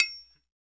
Index of /90_sSampleCDs/Spectrasonics - Supreme Beats - African Contemporary/PRC_SB Triangles/PRC_SB Triangles